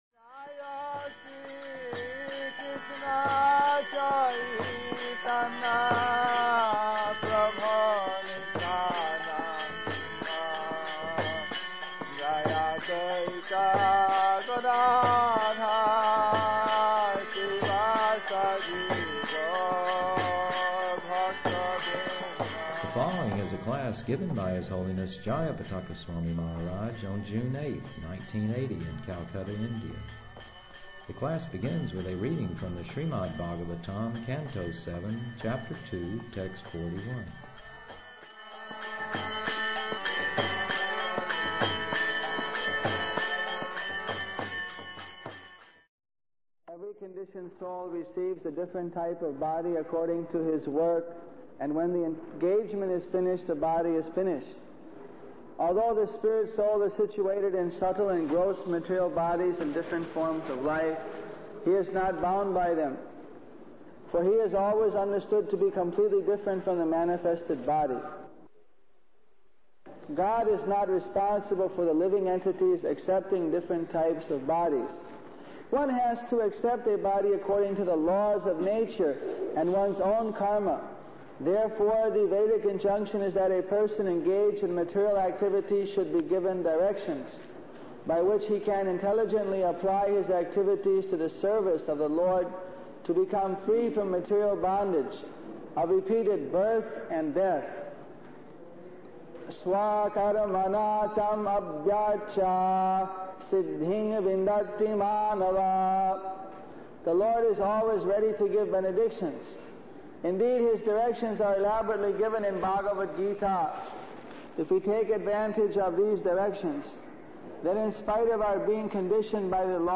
The class begins with a reading from the Srimad Bhagvatam canto 7, chapter 2, text 41.